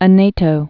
(ə-nātō, ä-nĕ-), Pico de